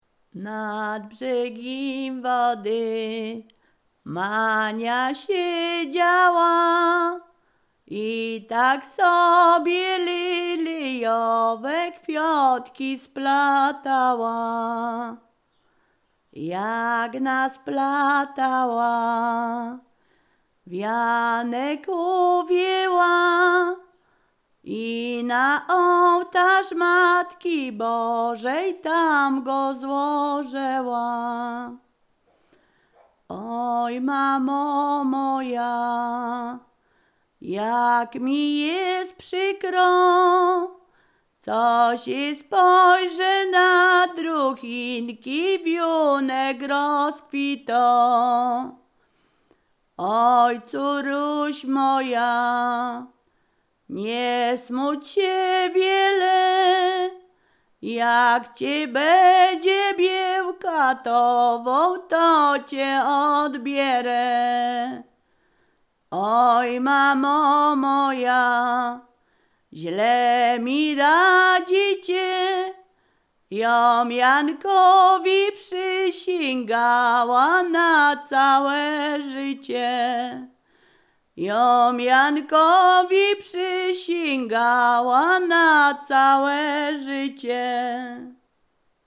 Ziemia Radomska
liryczne miłosne weselne